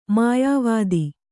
♪ mayāvādi